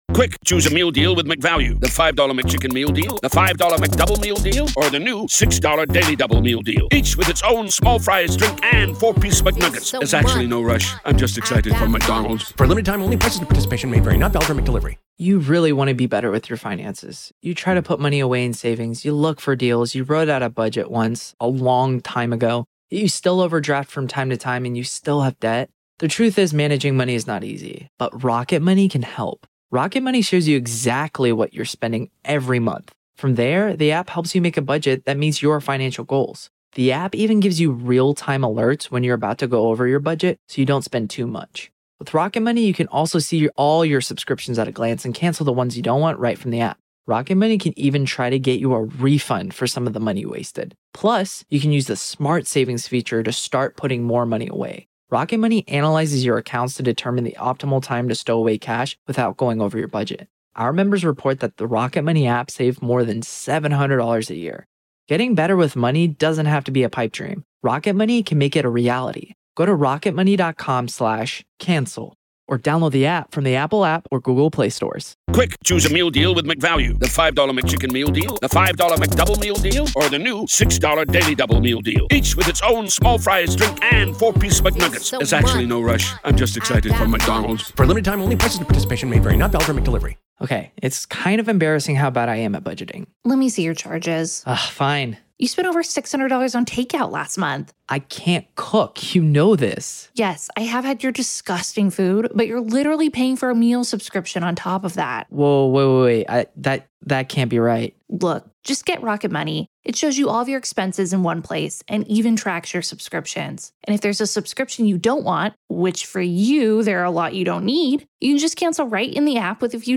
You’ll also hear a special archived interview clip with filmmaker James Fox, director of the acclaimed documentary Moment of Contact, discussing his investigative journey into the case.